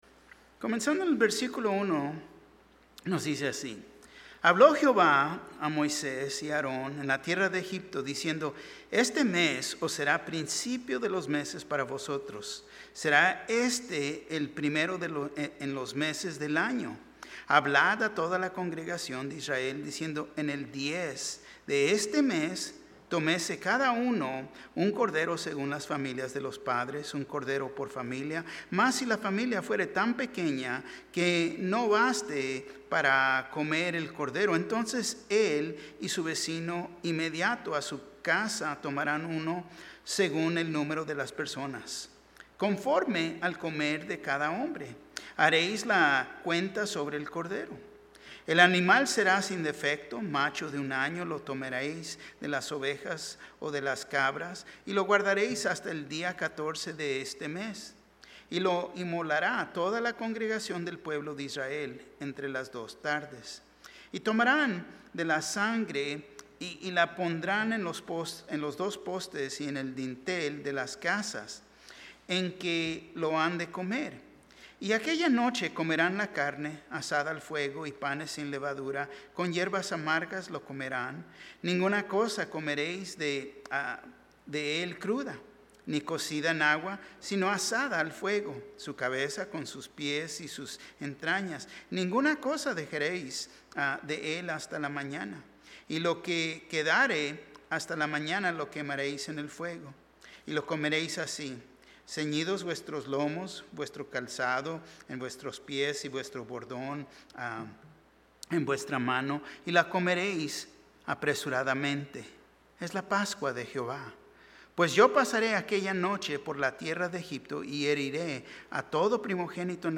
Un mensaje de la serie "Estudios Tématicos."